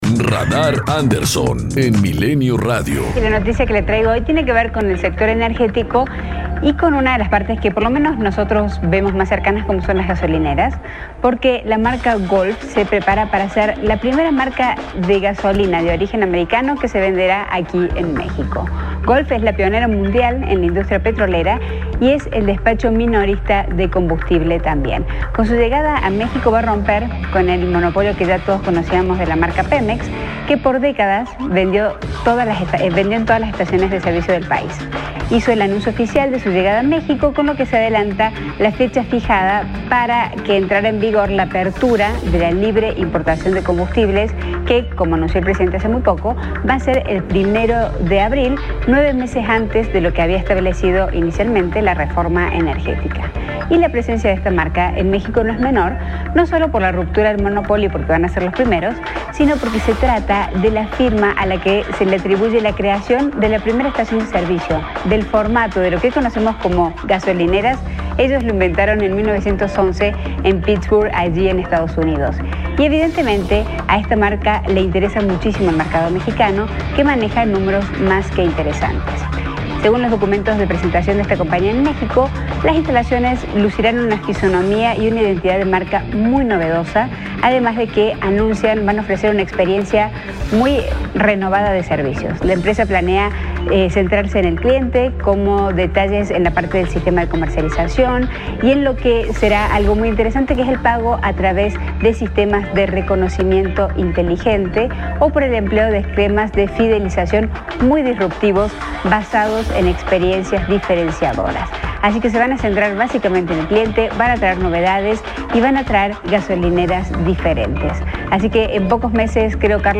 COMENTARIO EDITORIAL 140316